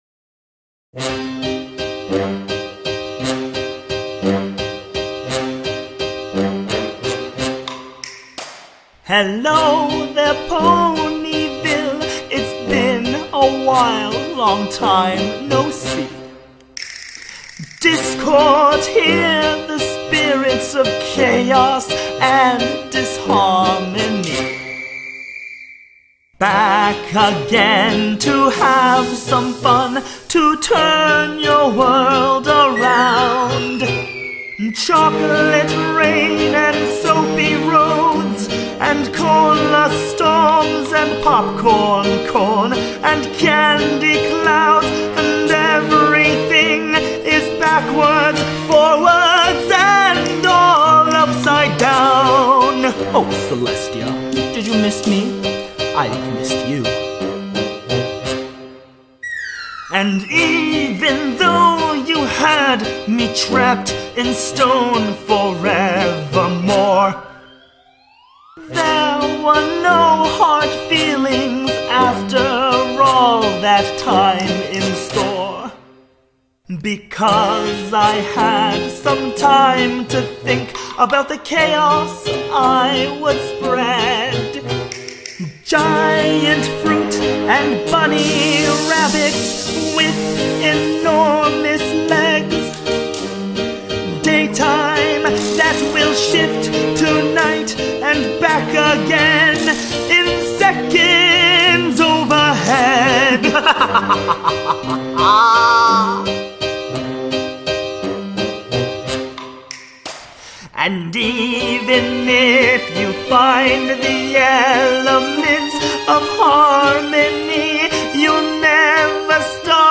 disney villain styled song